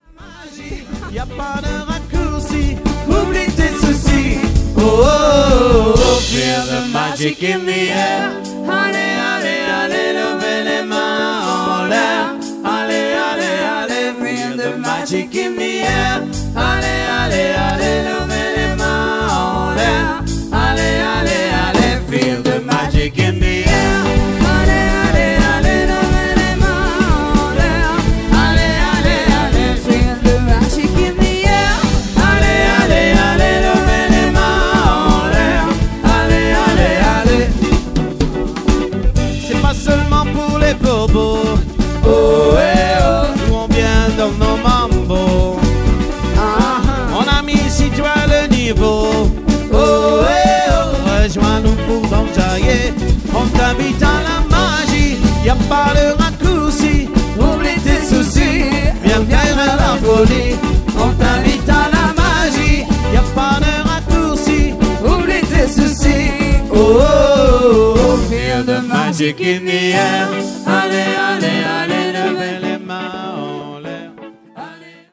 orchestre de variété internationale